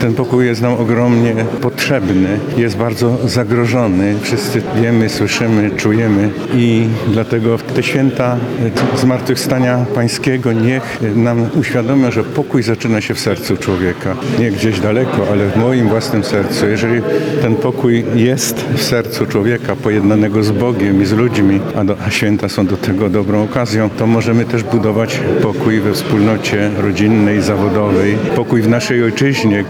- Pokój jest darem zmartwychwstałego Chrystusa - mówi arcybiskup Stanisław Budzik. Metropolita lubelski przewodniczył dziś mszy świętej w Archikatedrze Lubelskiej.